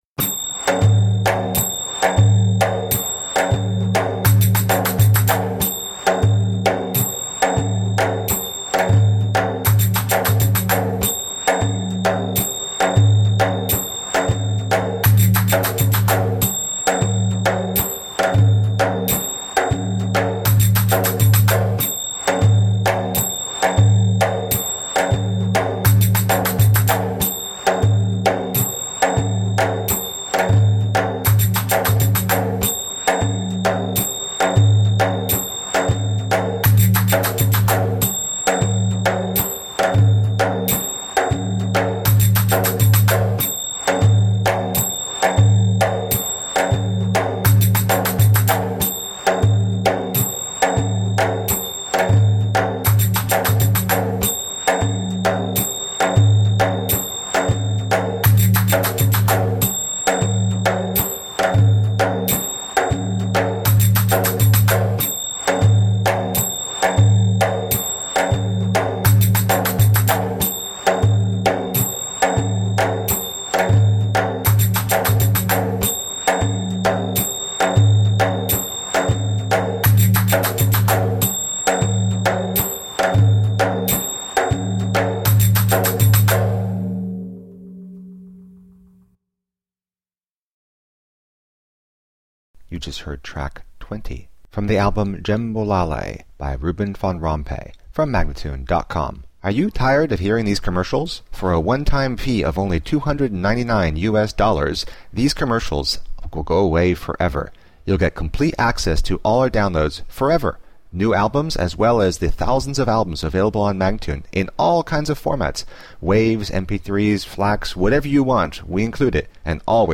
Passionate eastern percussion.
Tagged as: World, Loops, Arabic influenced